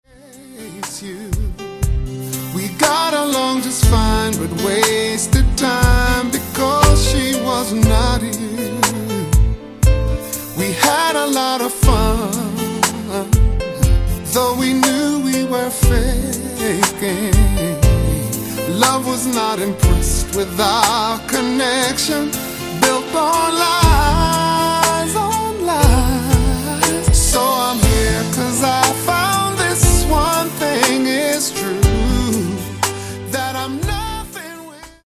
Genere:   RnB | Soul | Dance